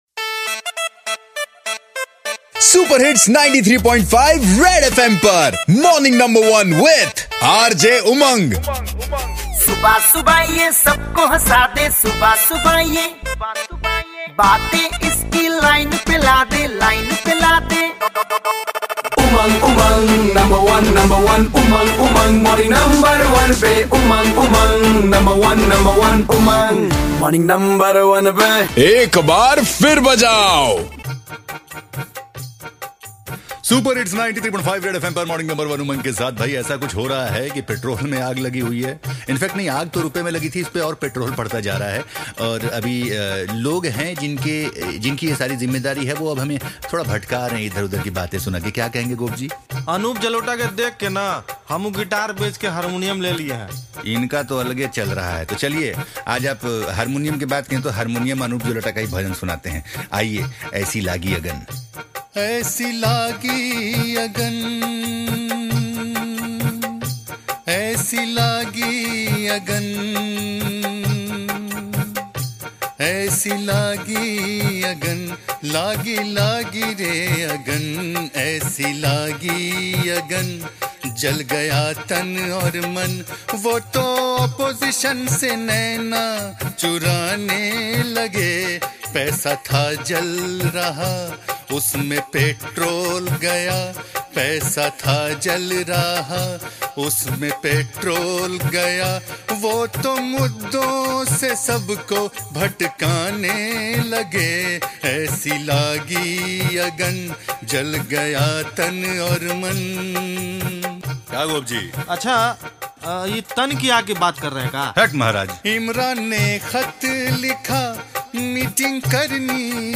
Anup Jalota chhaayein hue hain to unke hi andaaz mein suniye desh ka haal